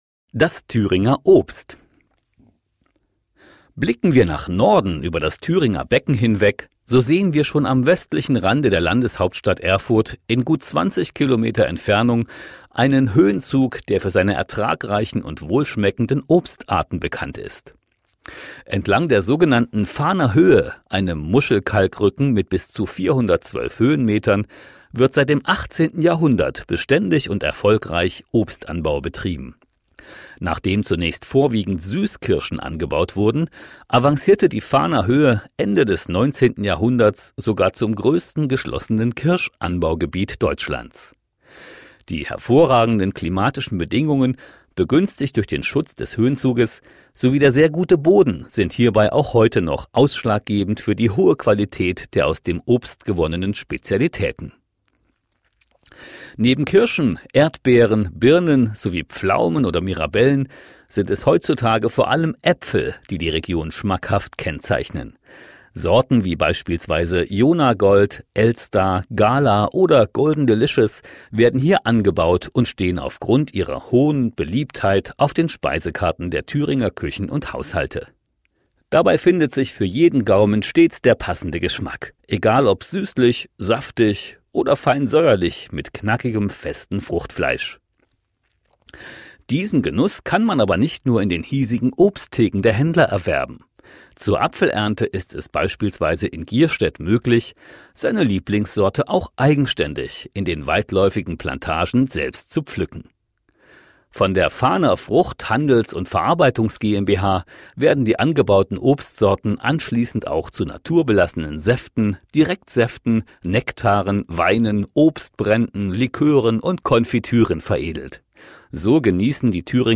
Audio-Guide